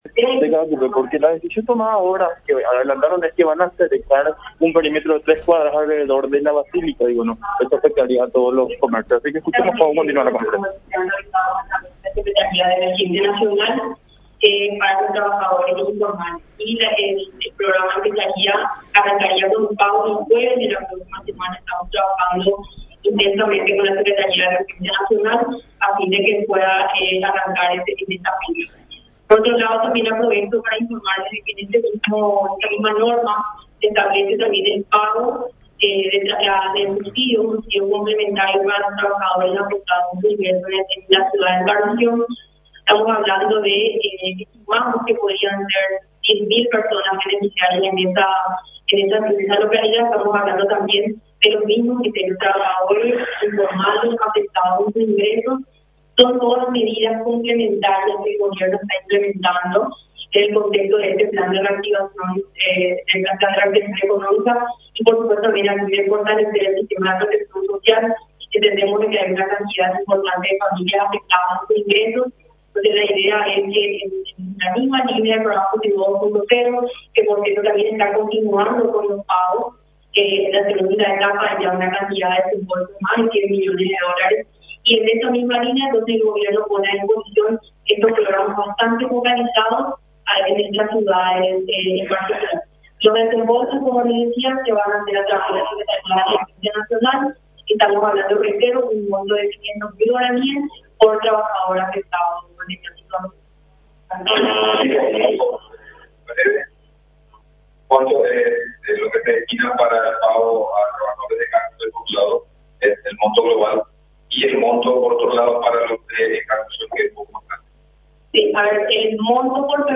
CONFERENCIA-MAZZOLENI.mp3